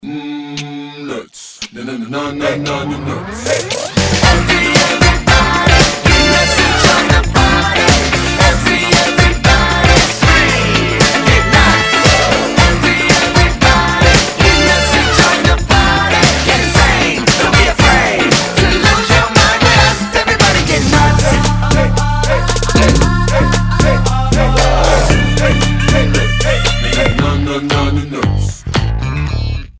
• Качество: 320, Stereo
поп
мужской вокал
прикольные
бодрые
Бодрый саундтрек из мультфильма 2017 года